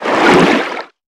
File:Sfx creature snowstalkerbaby swim 06.ogg - Subnautica Wiki
Sfx_creature_snowstalkerbaby_swim_06.ogg